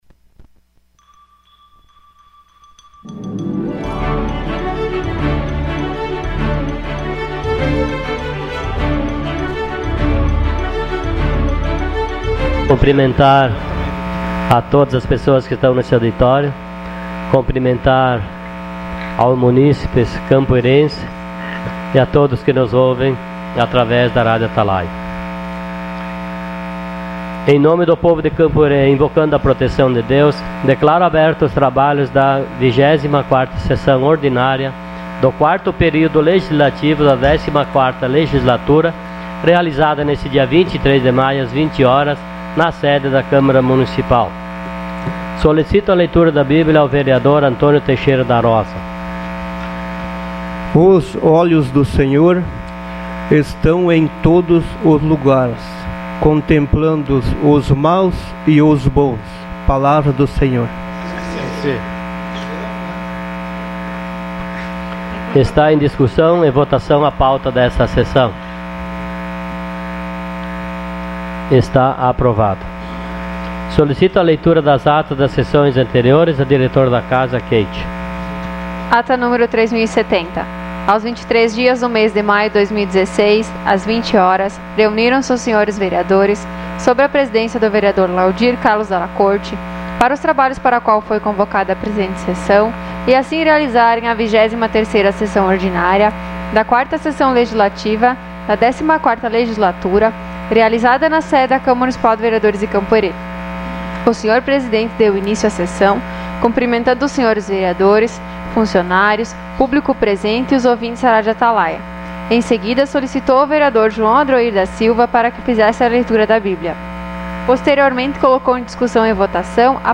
Sessão Ordinária dia 30 de maio de 2016.